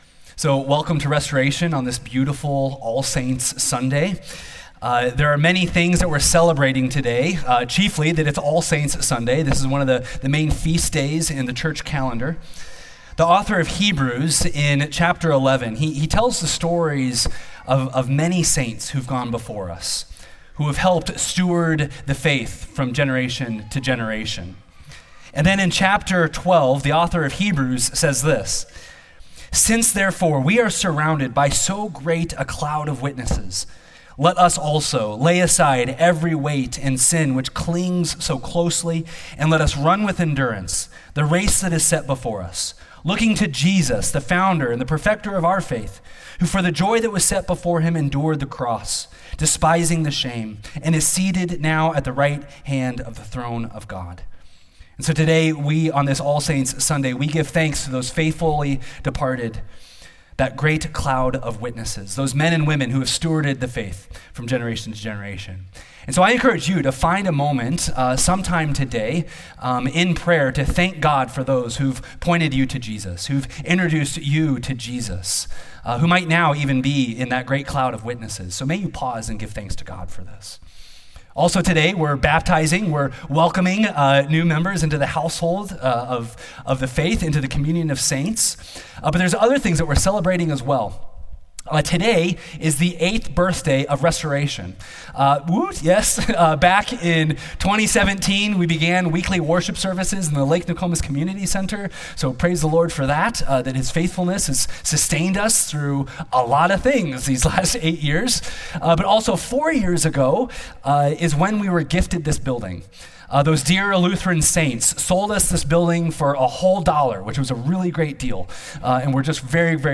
sermon11.02.MP3